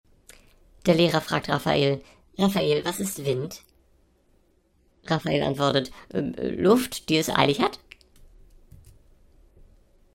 Die Witzschmiede bringt Dir jeden Tag einen frischen Witz als Audio-Podcast. Vorgetragen von unseren attraktiven SchauspielerInnen.
Comedy , Unterhaltung , Kunst & Unterhaltung